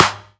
SNARE0.wav